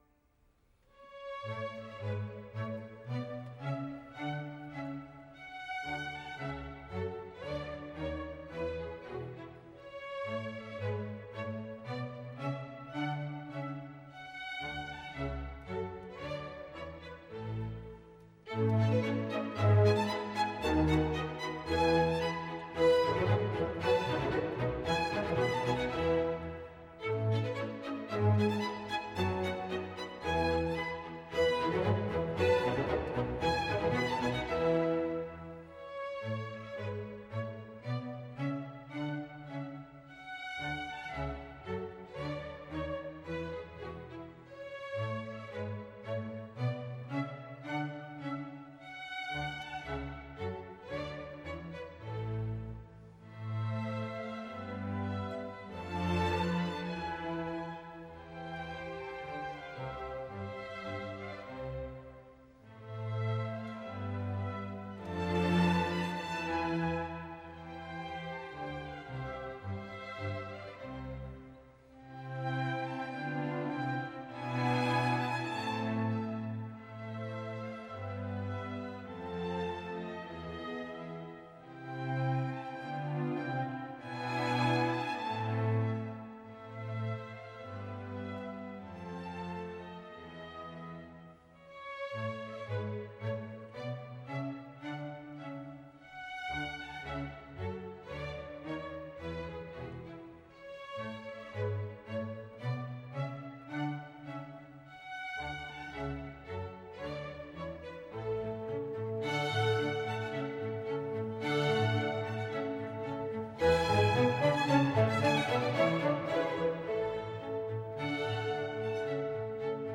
其动听程度不下于歌剧，风格介于“华丽”和“古典”之间，偶尔还流露一点“狂飙运动”的影响。